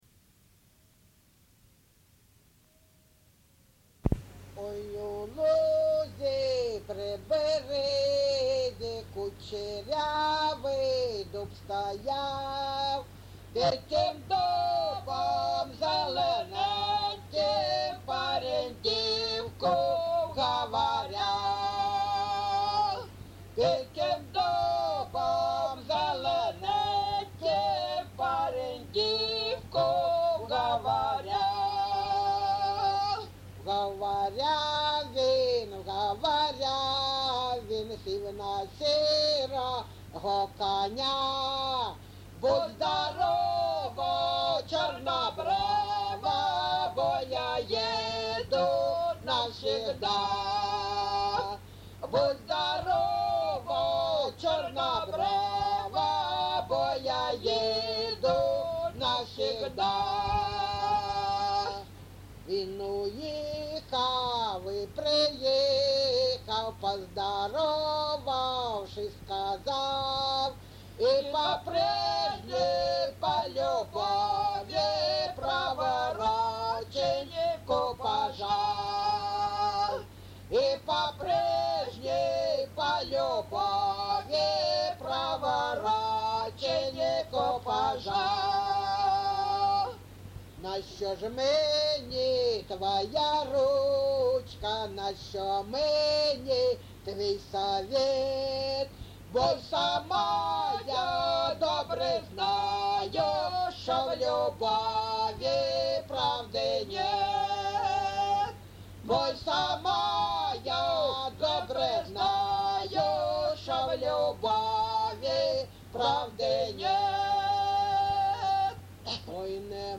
Місце записус. Григорівка, Артемівський (Бахмутський) район, Донецька обл., Україна, Слобожанщина